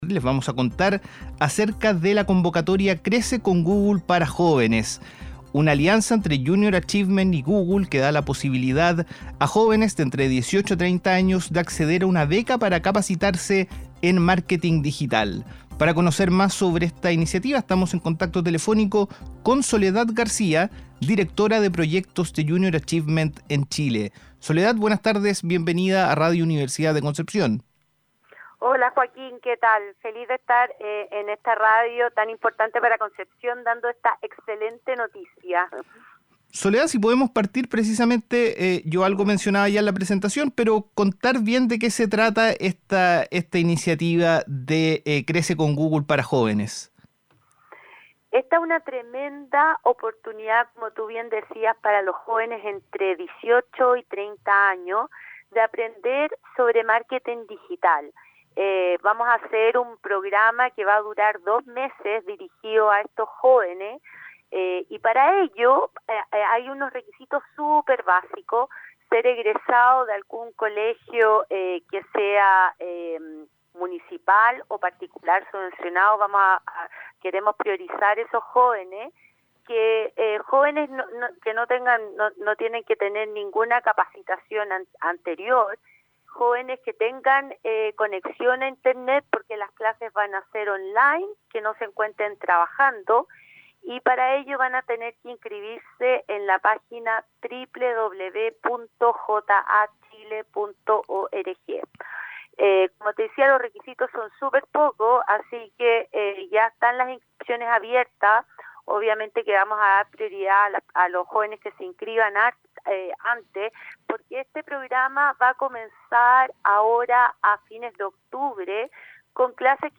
entrevista-curso-google-JAC.mp3